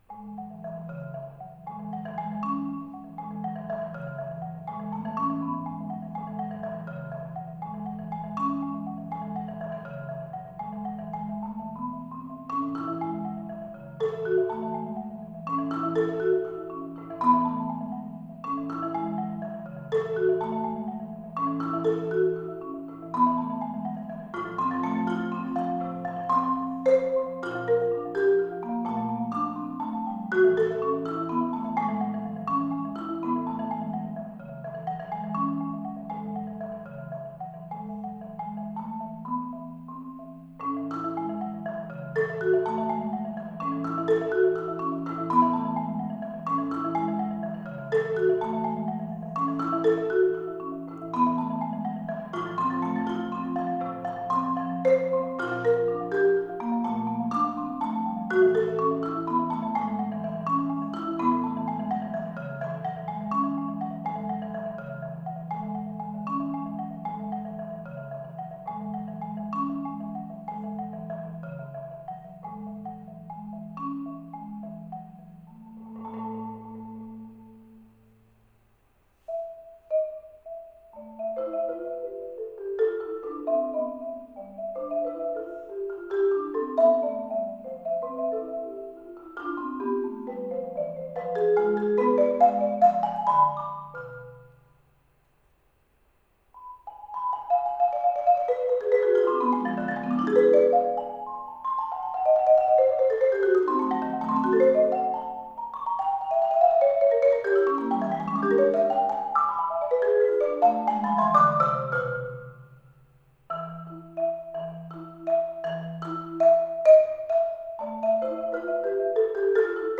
An examination of selected works for percussion